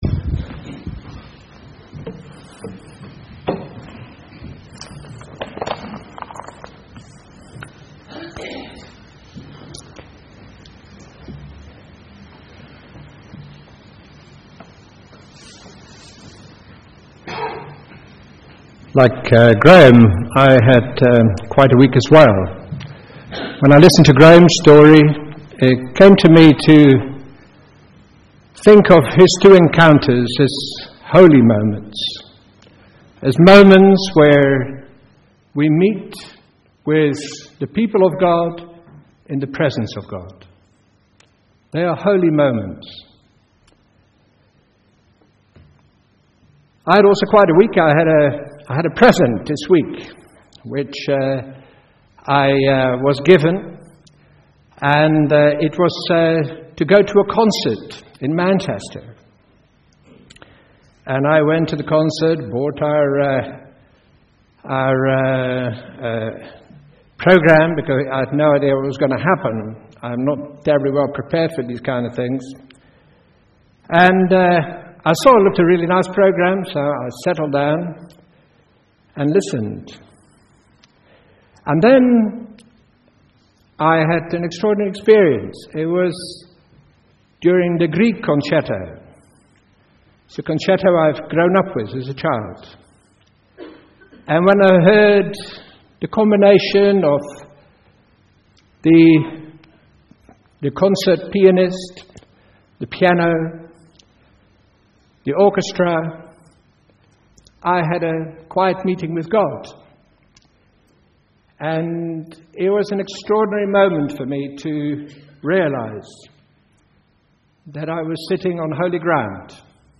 A message from the series "The Vision."